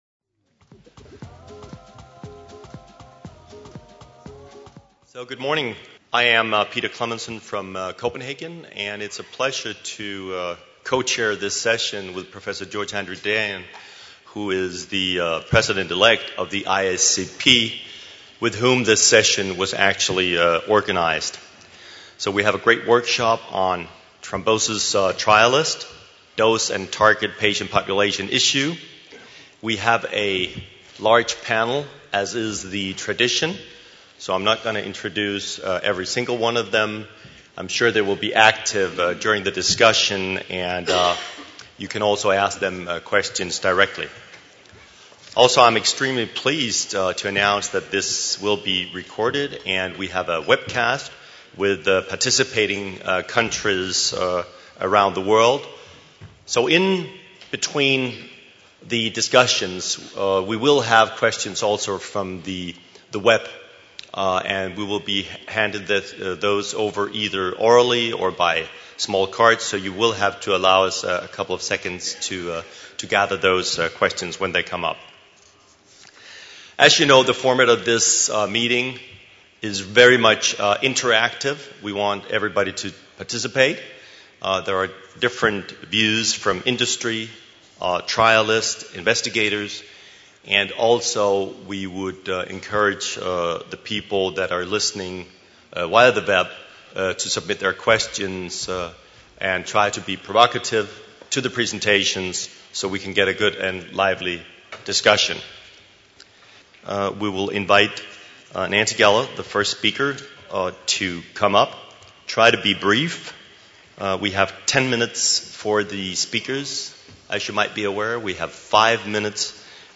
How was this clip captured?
Cardiovascular Clinical Trialists (CVCT) Forum – Paris 2012 - Workshop 1 : How to secure the optimal dose(s) for phase III?